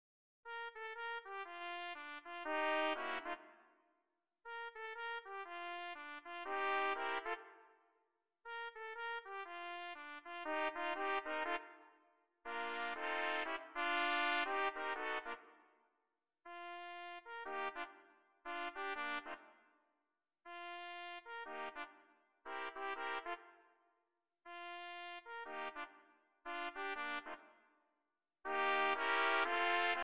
swing music